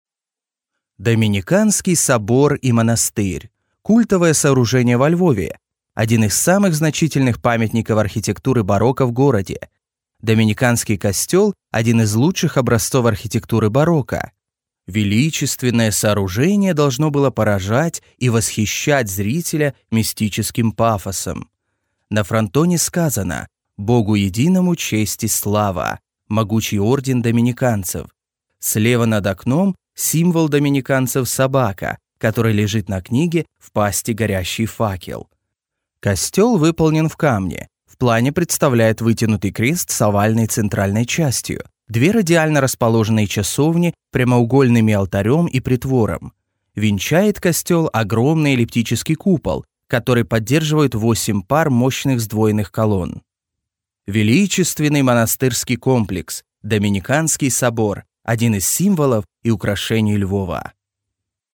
Russian and Ukrainian VO services (native in both) Professional studio, quick turnaround
Sprechprobe: eLearning (Muttersprache):
Young Adult Male Distinct articulation, educational, confident, believable and expressive style